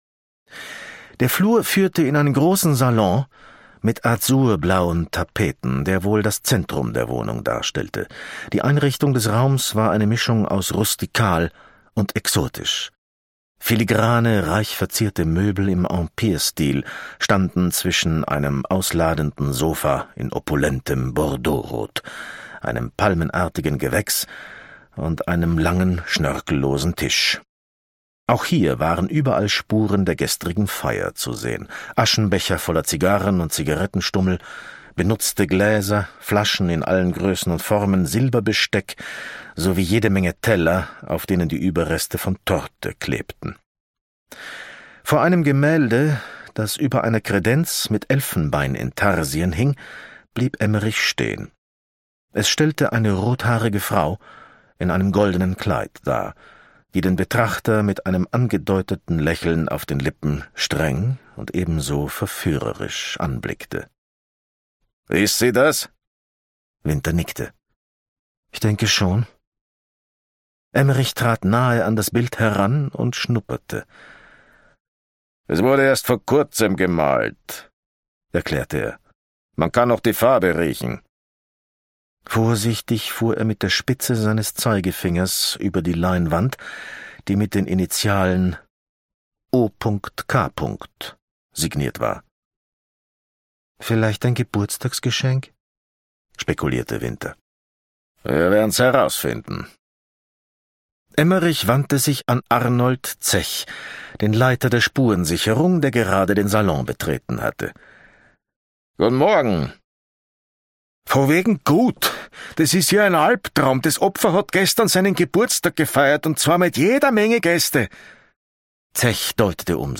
Cornelius Obonya (Sprecher)
Ungekürzte Lesung